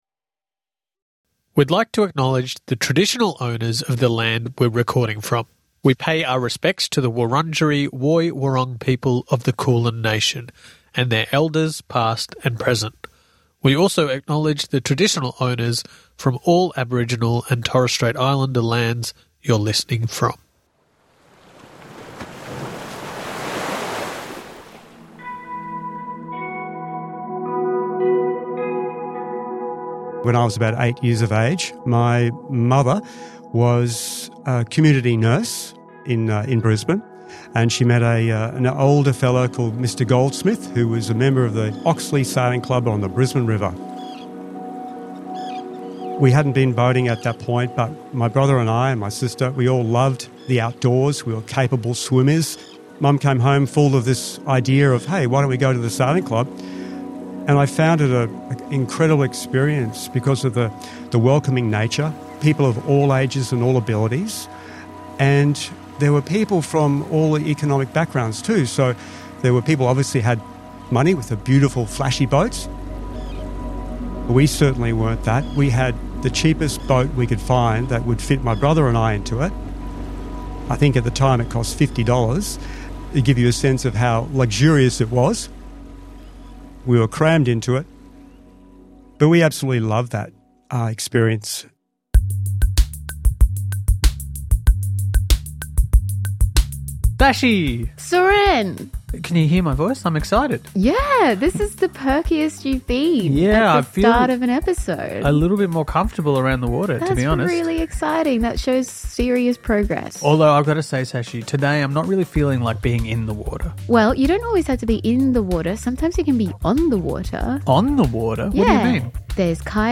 hosted by comedians